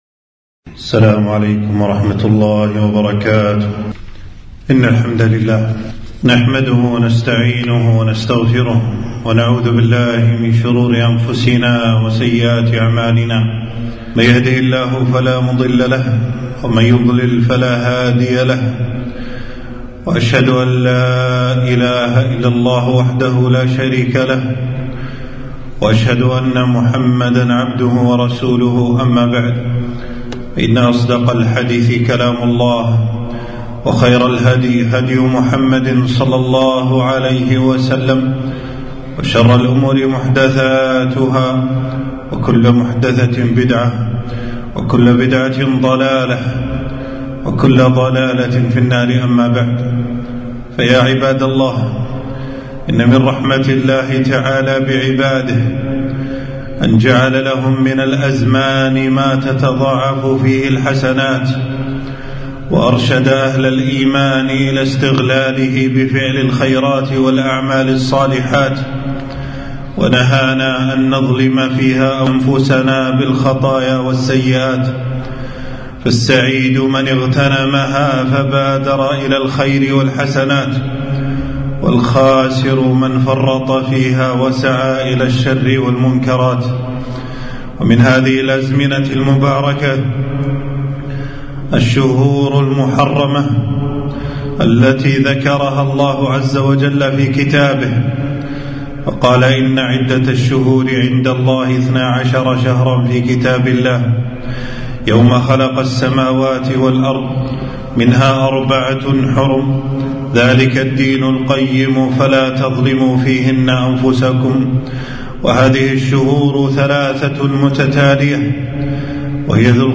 خطبة - شهر الله المحرم فضله وما يشرع فيه - دروس الكويت